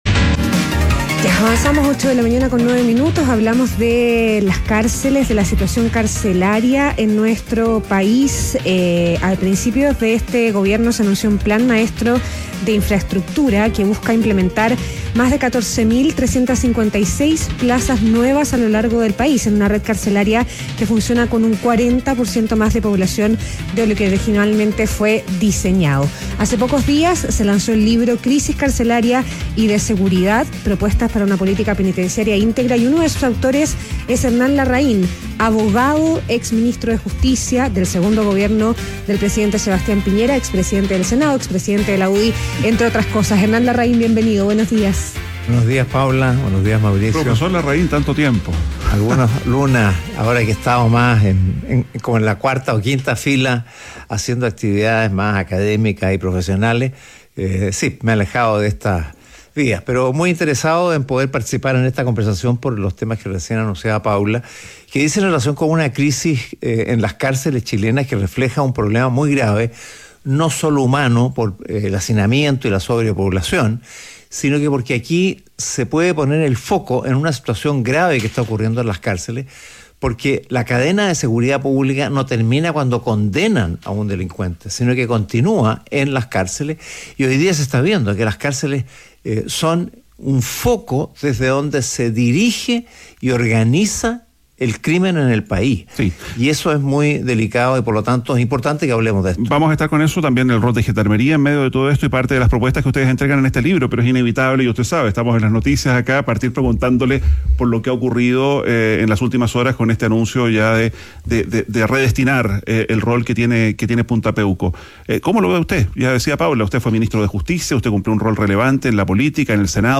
Entrevista Hernán Larraín, abogado y exministro de Justicia - ADN Hoy